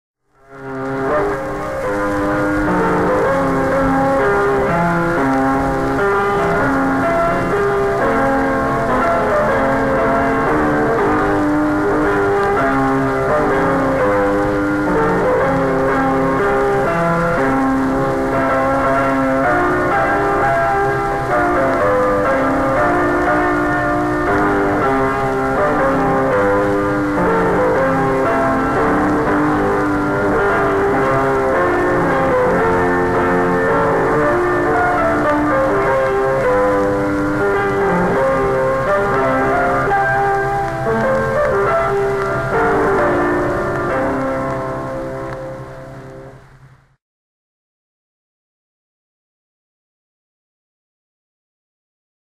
lively piano rendition